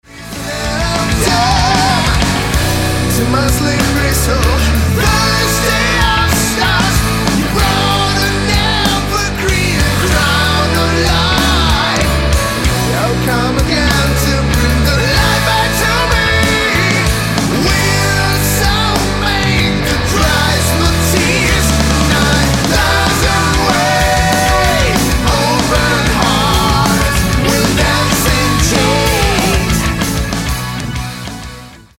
chitarre
tastiere
batteria